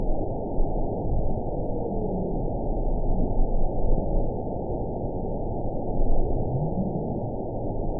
event 922020 date 12/25/24 time 10:19:45 GMT (5 months, 3 weeks ago) score 8.15 location TSS-AB10 detected by nrw target species NRW annotations +NRW Spectrogram: Frequency (kHz) vs. Time (s) audio not available .wav